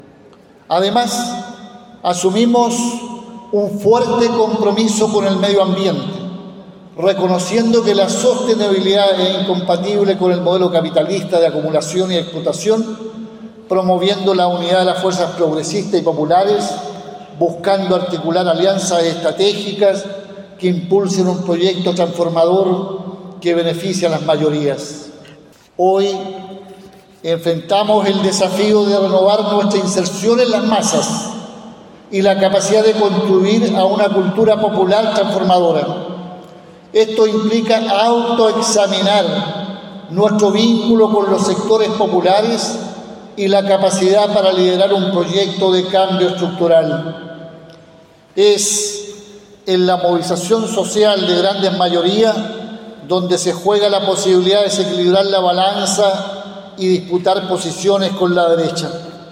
Durante la inauguración, el Presidente del Partido Comunista Lautaro Carmona, realizó un discurso en dónde abarcó de manera detallada los puntos más importantes de la política y el debate de los comunistas.